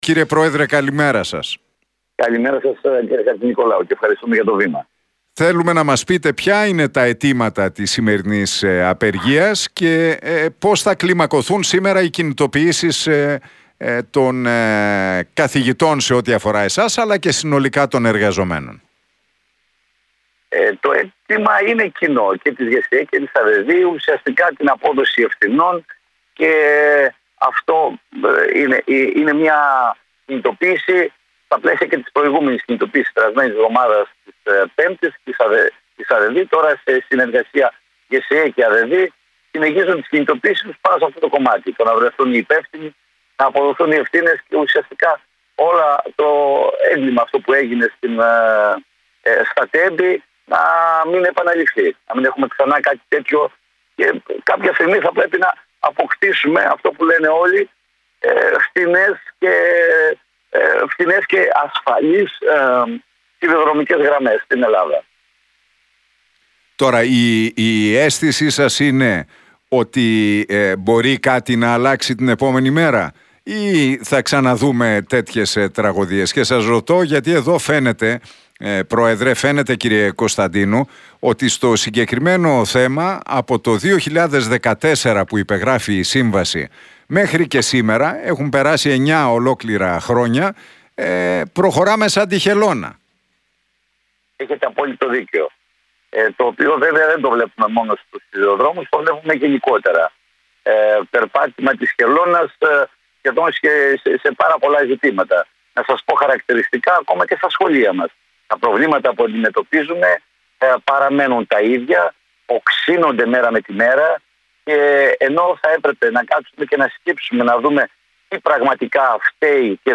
Απεργία: Τα αιτήματα από ΓΣΕΕ, ΑΔΕΔΥ, ΟΛΜΕ και ΔΟΕ - Τι είπαν εκπρόσωποι στον Realfm 97,8